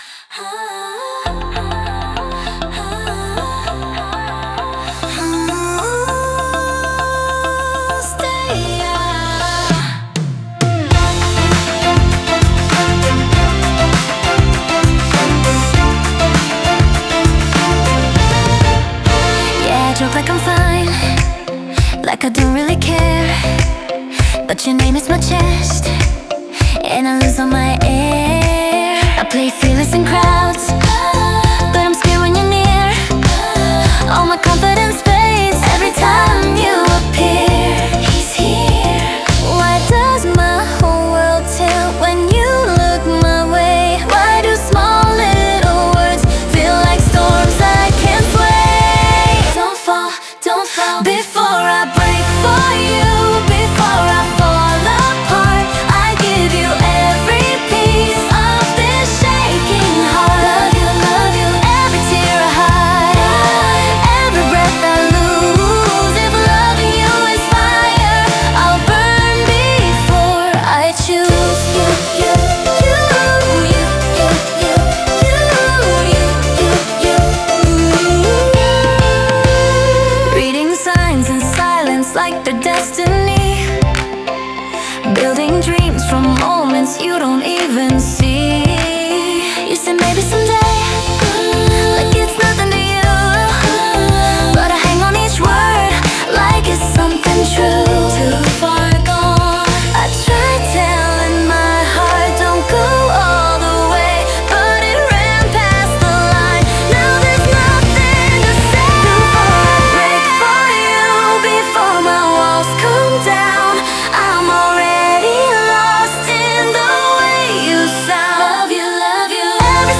可愛らしい音像とは対照的に、恋にすべてを差し出す強い愛と、壊れる寸前の切なさが同時に描かれている構成。